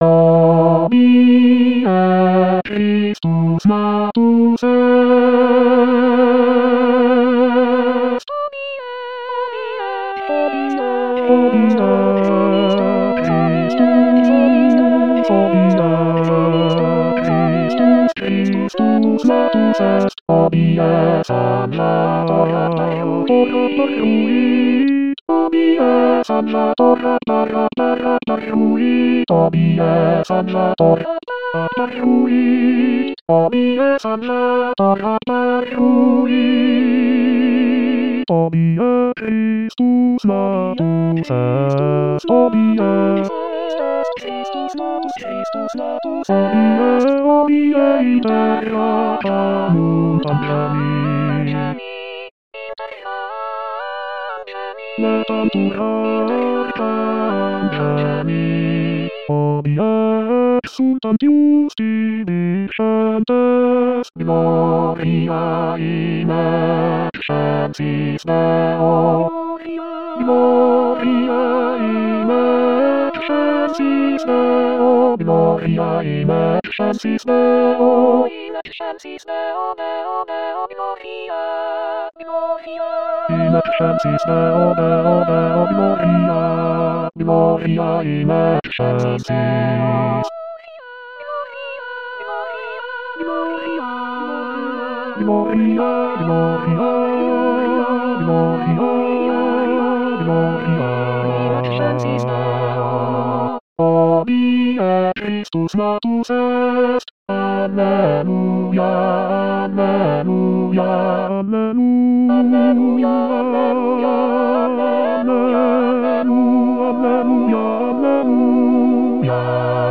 Bass Bass 2